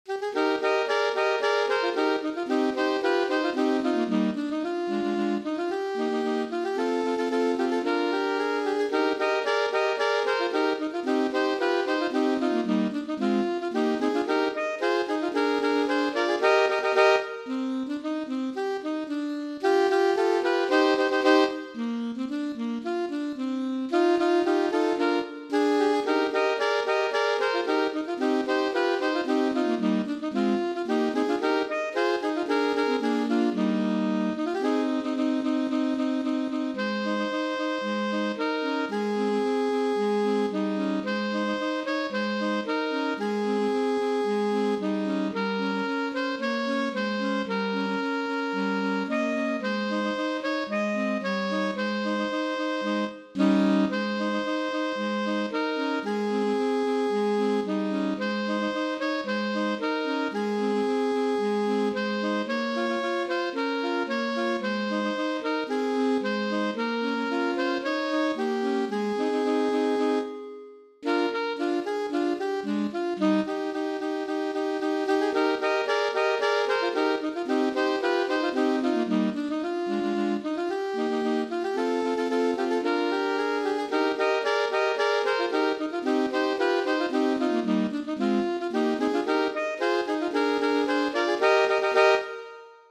Voicing: Saxophone Trio (AAA)